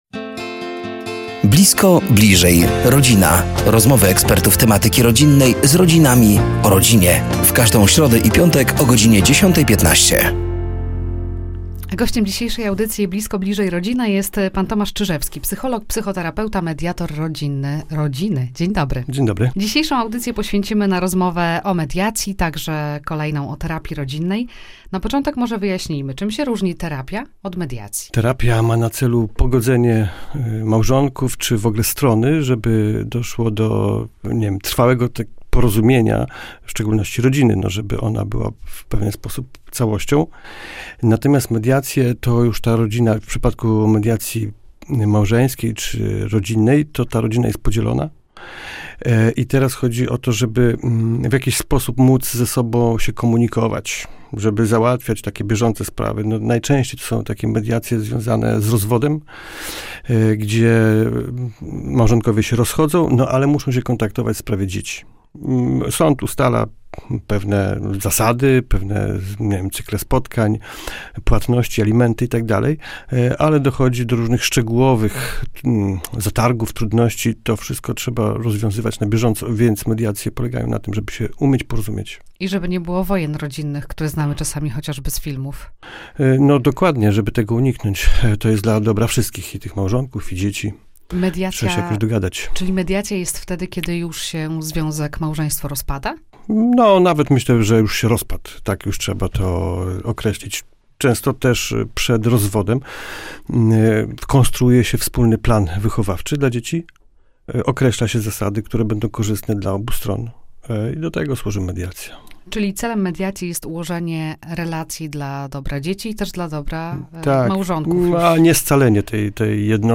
Do studia zaproszeni są eksperci w temacie rodziny i rodzicielstwa.